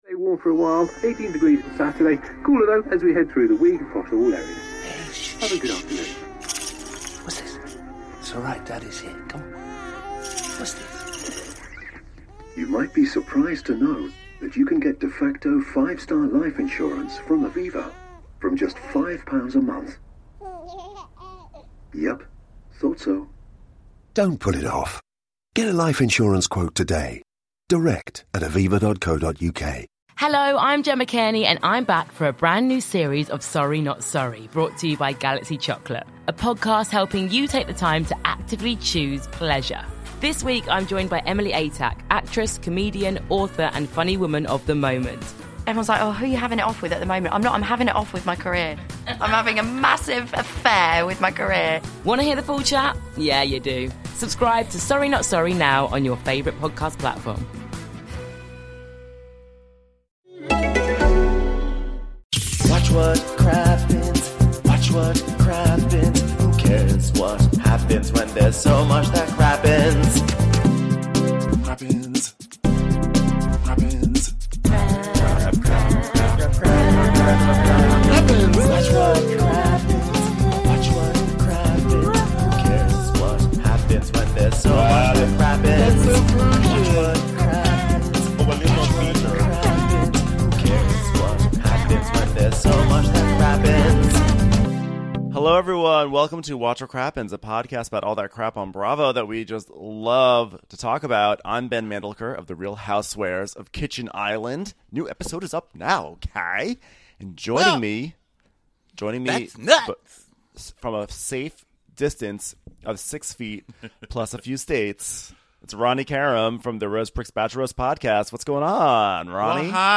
RHONJ: Pulling Out All The Staubs - Live from Instagram!
Part 3 of the Real Housewives of New Jersey reunion closes out an explosive season with Danielle Staub making her tear-less goodbye to the franchise (until she comes back). We were supposed to recap this episode live in Salt Lake City, but due to Coronavirus precautions, we took our "live show" to Instagram instead.